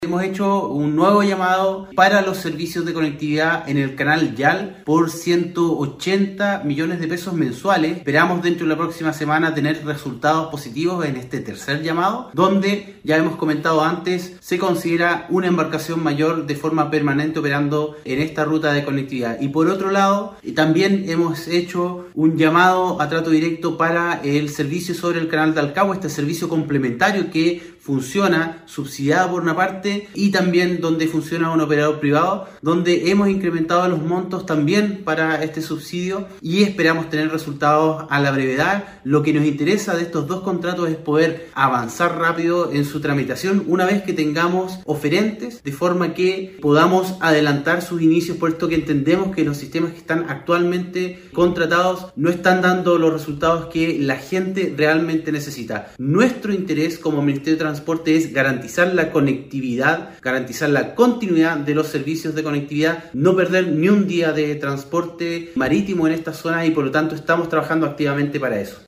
Así lo dio a conocer el seremi de Transportes y Telecomunicaciones, Pablo Joost: